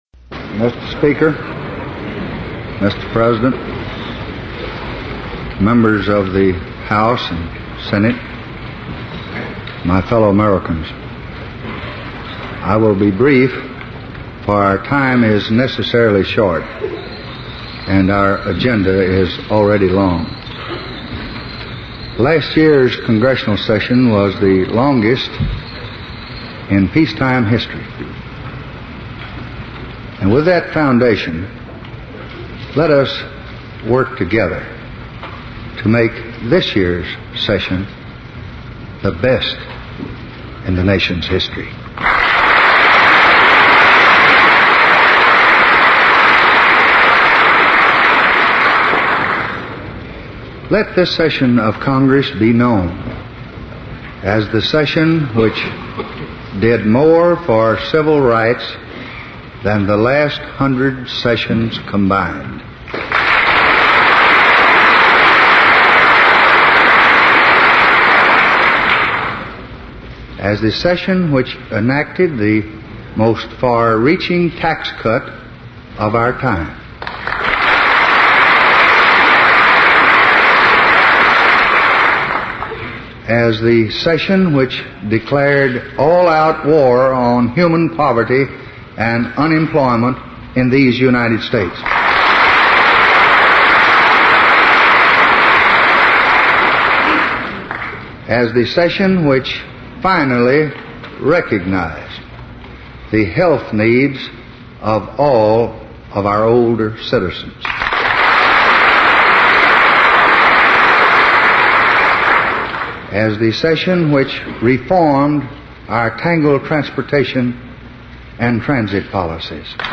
Tags: Lyndon Baines Johnson Lyndon Baines Johnson speech State of the Union State of the Union address President